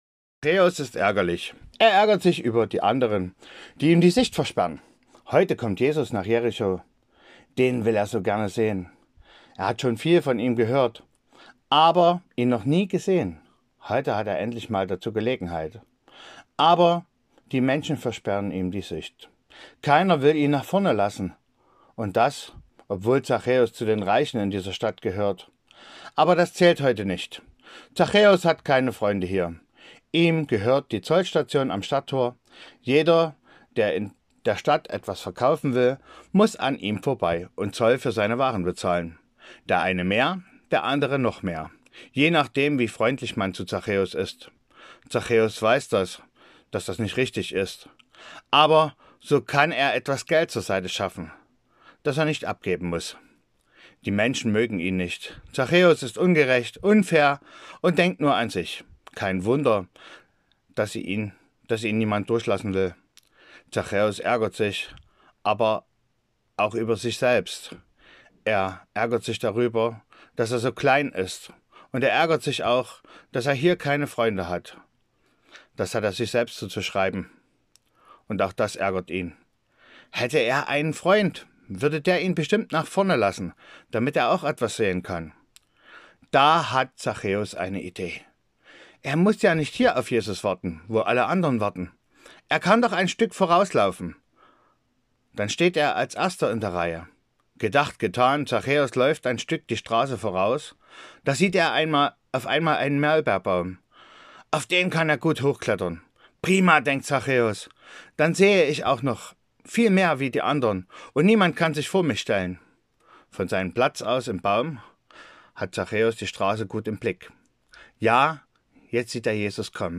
Hier die Hörspielgeschichten